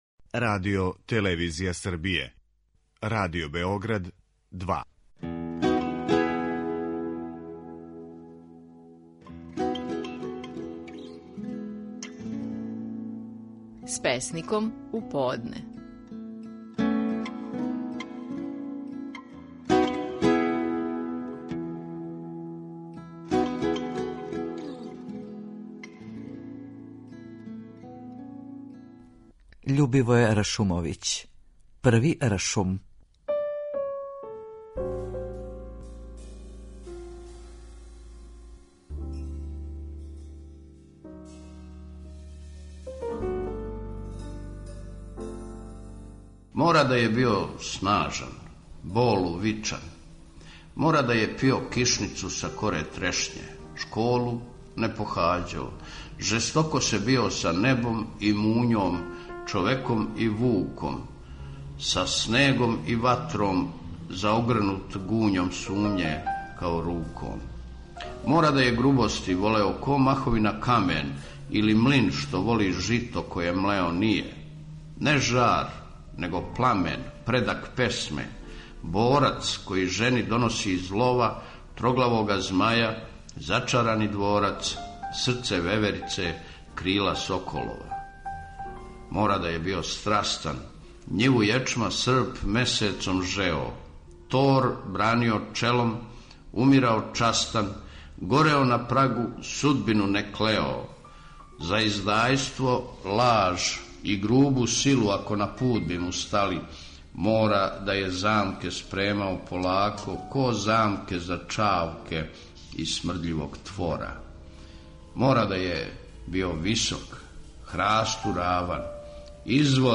Стихови наших најпознатијих песника, у интерпретацији аутора.
Љубивоје Ршумовић говори своју песму „Први Ршум".